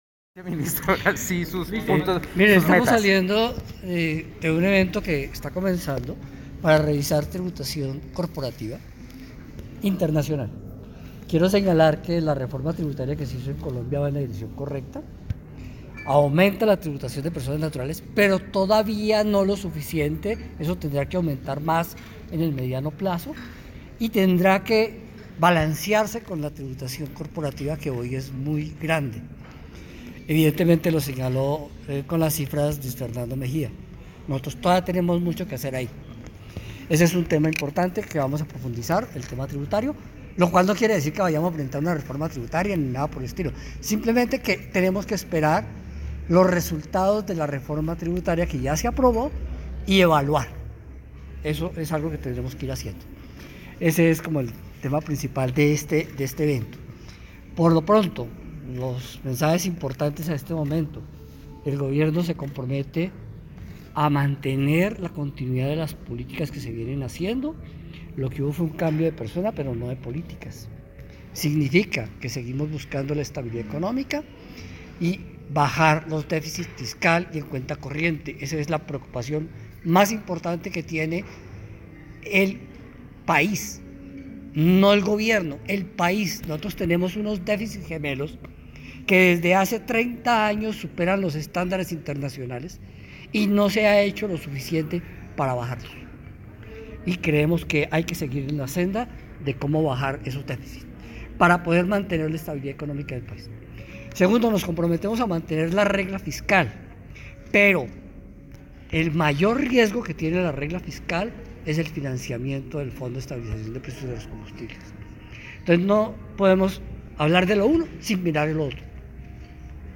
Declaraciones del Ministro Ricardo Bonilla al término de su conferencia sobre tributación equitativa